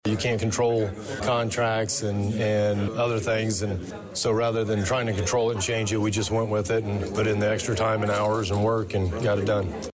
During a Wednesday (May 29th) open house at the new Danville Police Community Training Center, Police Chief Christopher Yates thanked everybody from city officials to area residents for putting a $1 million dollar state grant to work.